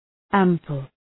Προφορά
{‘æmpəl}